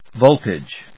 /vóʊltɪdʒ(米国英語), vˈəʊltɪdʒ(英国英語)/